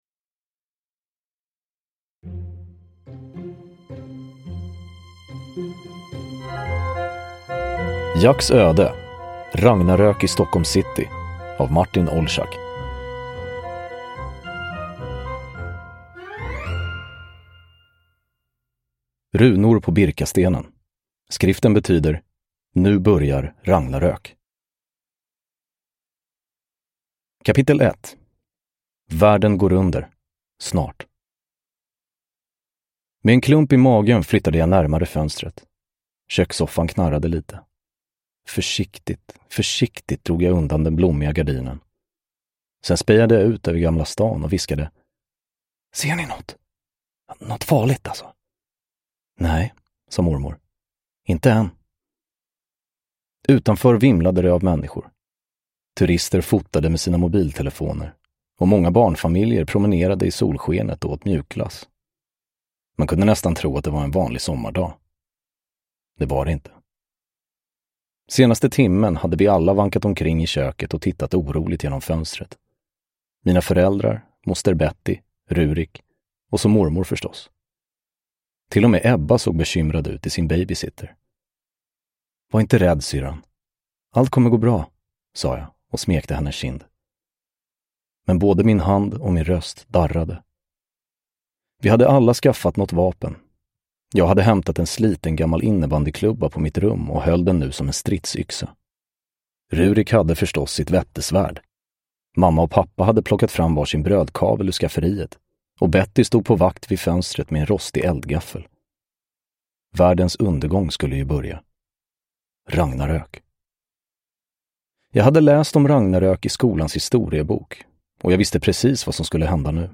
Ragnarök i Stockholm city – Ljudbok – Laddas ner
Uppläsare: Anastasios Soulis